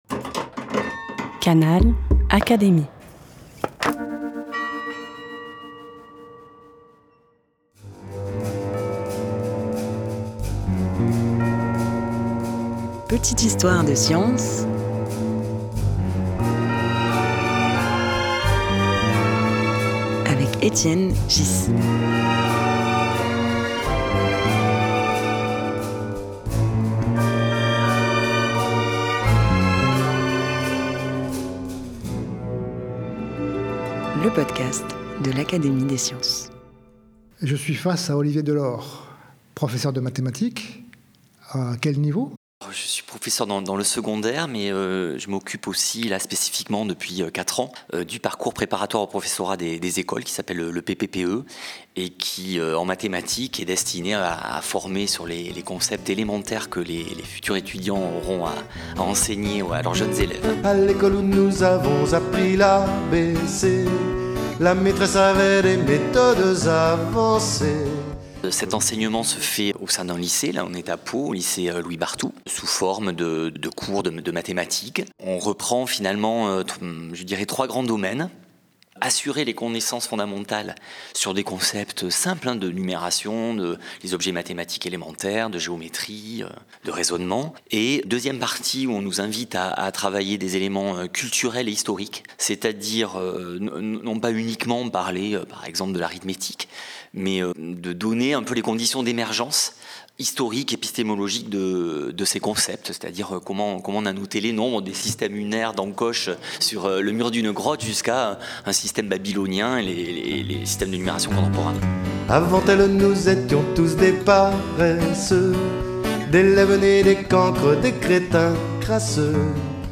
Il s’agit d’un système expérimental innovant dans lequel les étudiants travaillent toutes les matières dès la première année universitaire, dans un lycée mais aussi à l’université. Au micro d’Étienne Ghys, il raconte avec passion le plaisir d’enseigner, et encore plus celui d’accompagner les futurs professeurs dans leurs premiers pas.
Un podcast animé par Étienne Ghys, proposé par l'Académie des sciences.